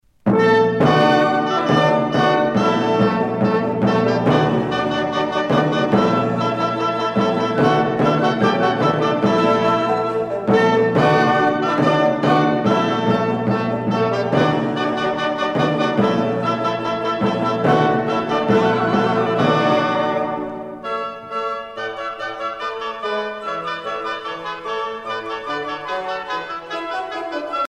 Musique maçonnique française du XVIIIe siècle
Pièce musicale éditée